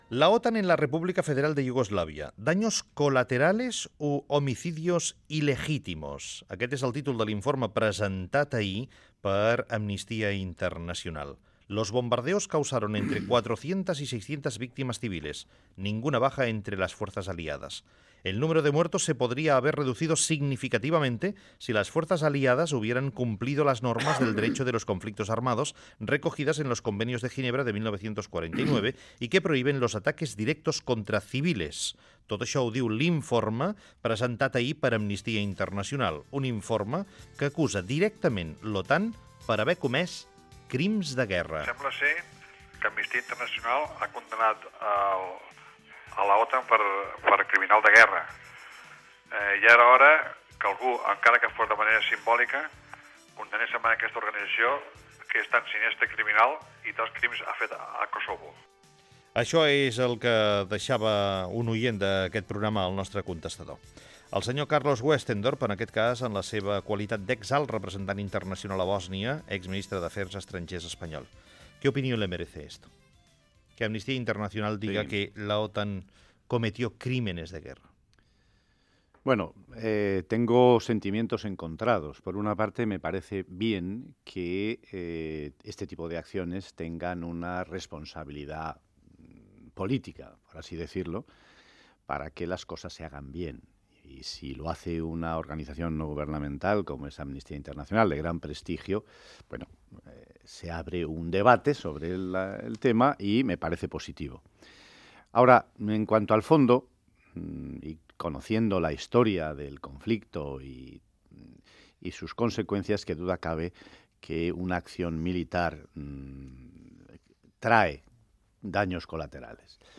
Fragment d'una entrevista a Carlos Westendorp, ex ministre d'afers exteriors espanyol i ex alt representant europeu a Bòsnia.
Info-entreteniment
FM